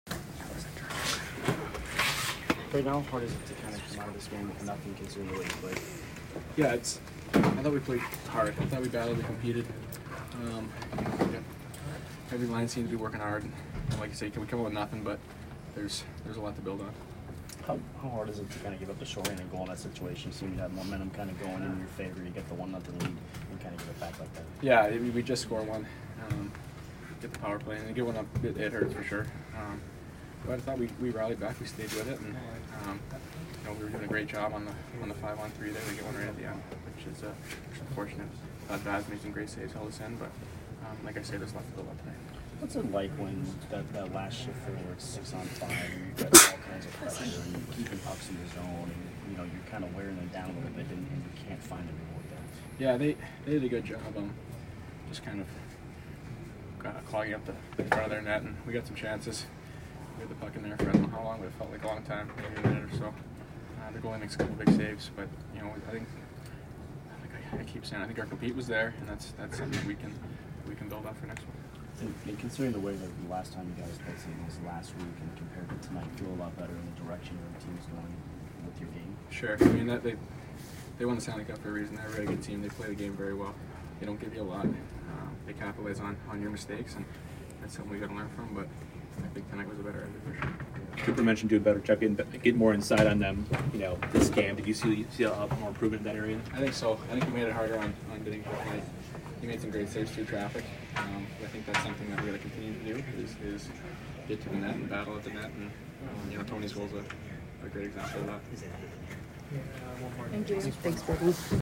Point post-game 11/27